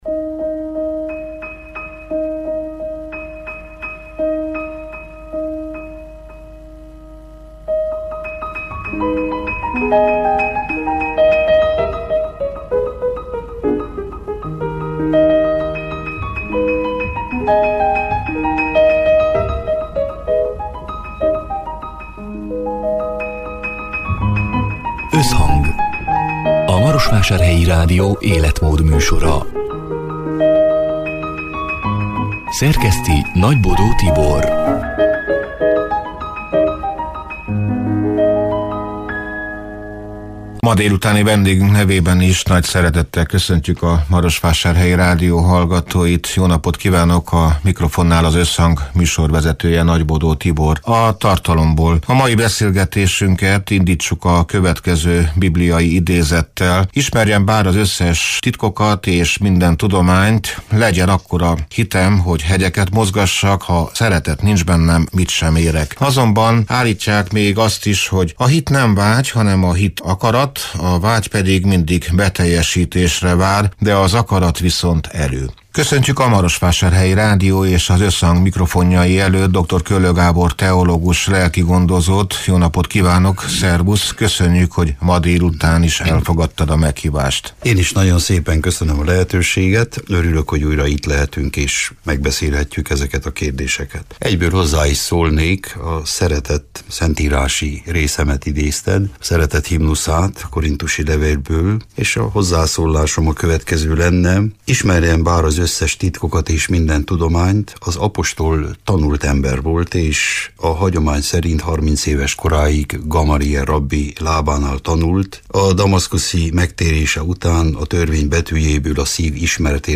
Vajon, hogyan fonódik össze e két erő az ember életében – hétköznapokban, nehézségekben, örömökben? Tartsanak velünk egy őszinte, szívből jövő beszélgetésre!